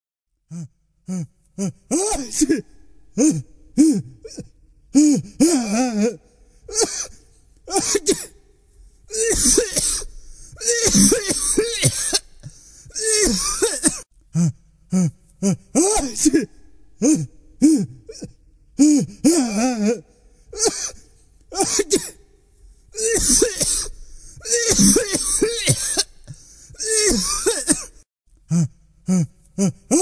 Cough & Sneezing
This is a Human Voice of Cough Sneezing recorded in real time.
CoughSneezing.mp3